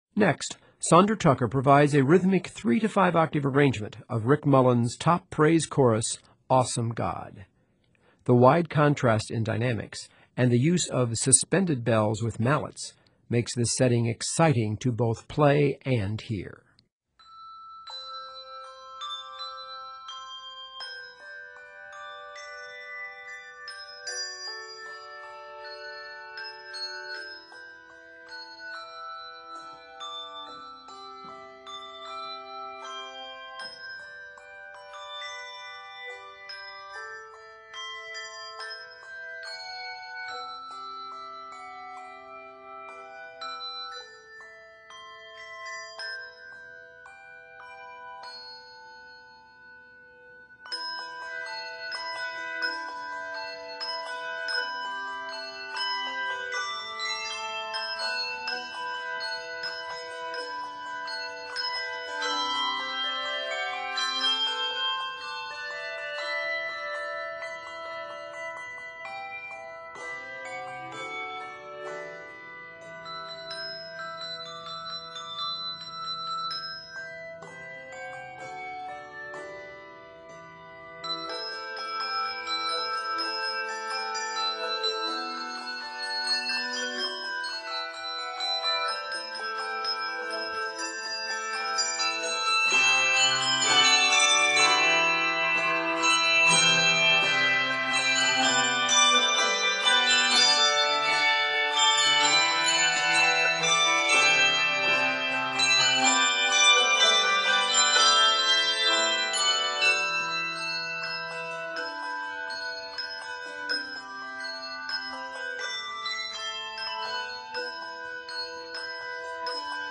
rhythmic arrangement